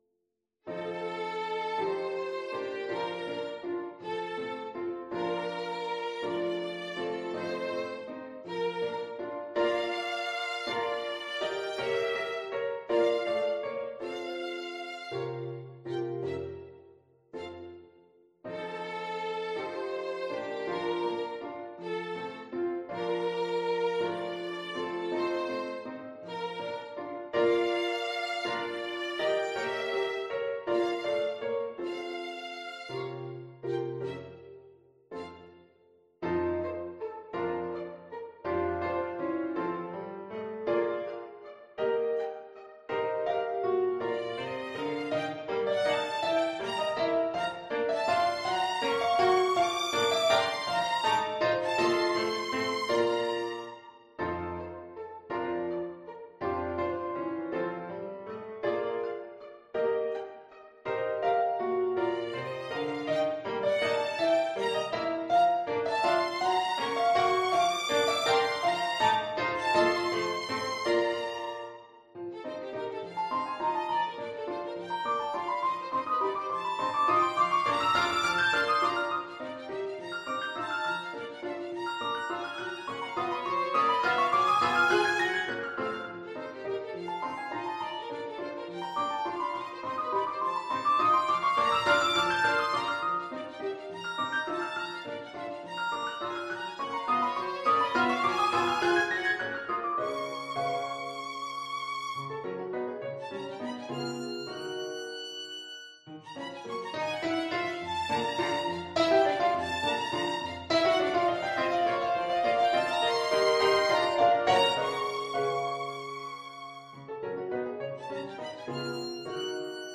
Violin Sonata in B-flat major